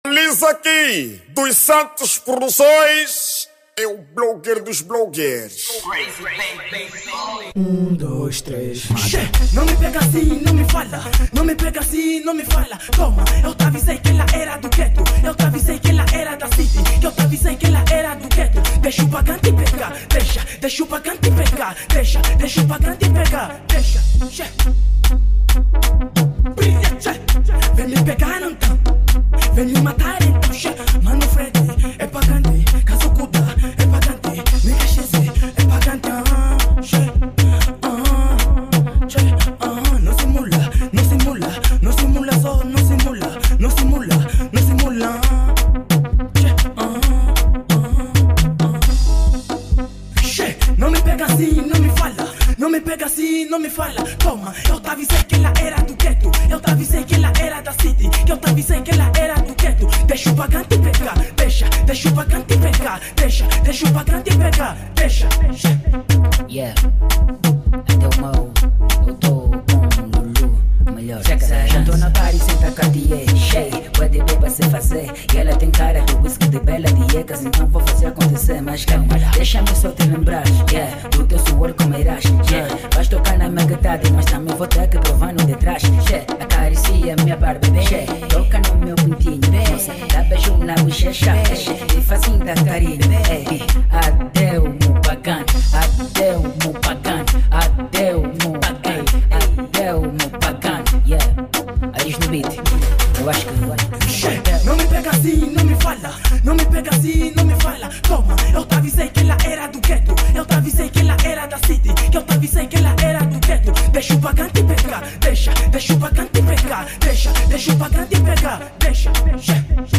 Categoria:  Afro House